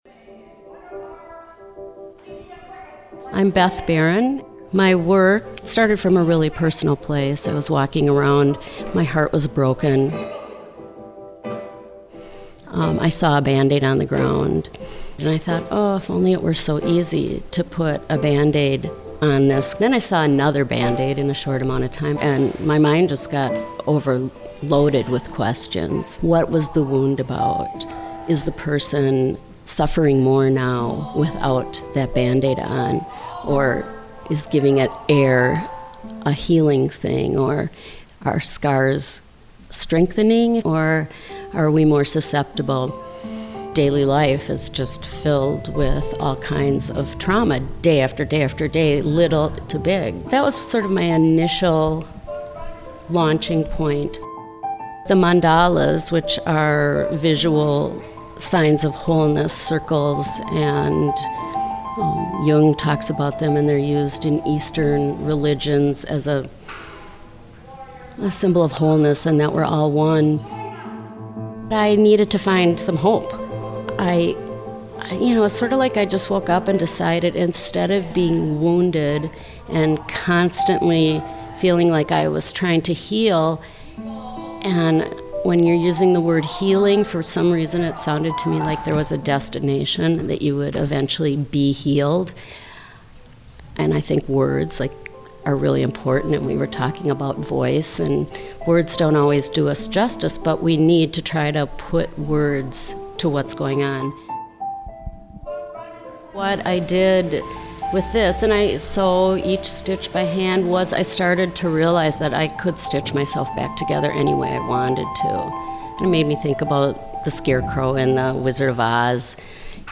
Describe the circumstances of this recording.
ENOUGH Violence: Artists Speak Out opening reception